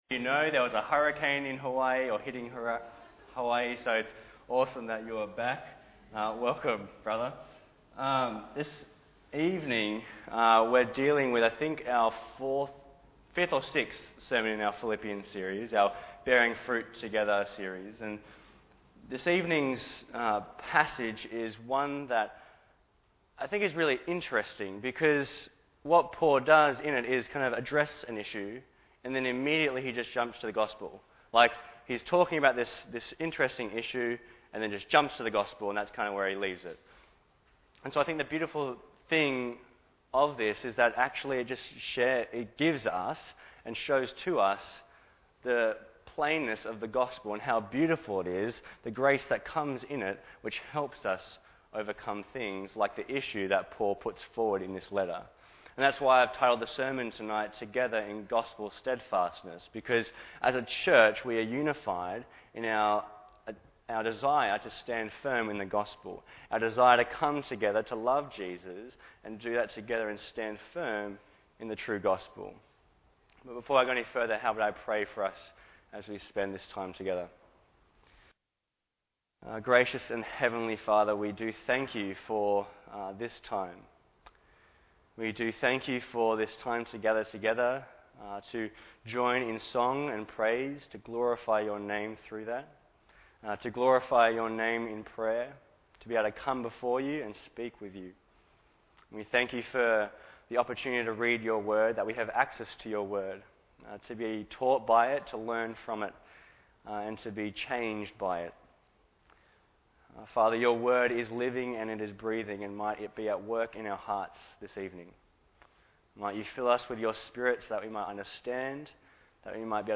Bible Text: Philipians 3:1-11 | Preacher